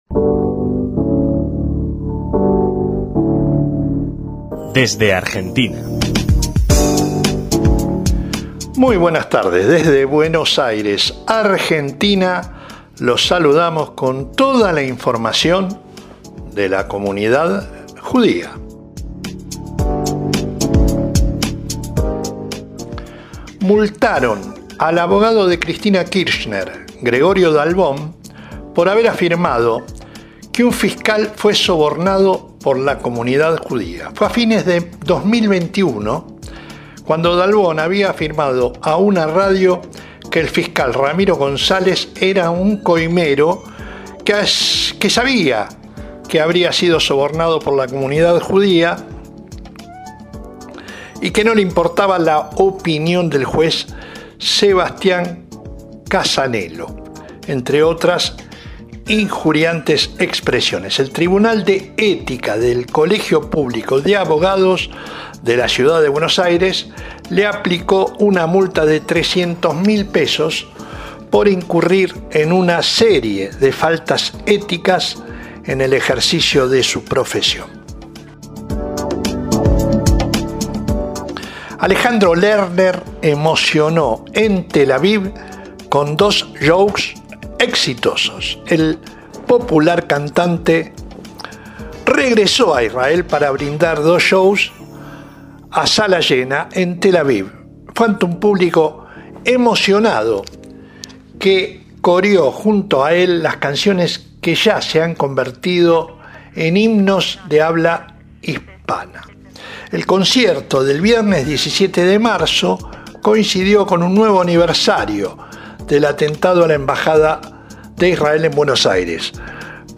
Un nuevo informa bisemanal